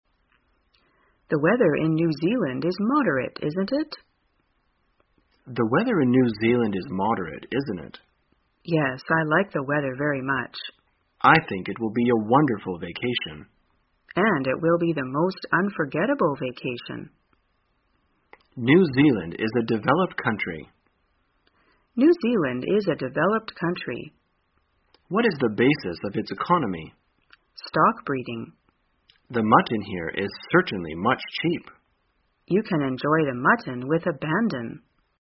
在线英语听力室生活口语天天说 第352期:怎样谈论气候与经济的听力文件下载,《生活口语天天说》栏目将日常生活中最常用到的口语句型进行收集和重点讲解。真人发音配字幕帮助英语爱好者们练习听力并进行口语跟读。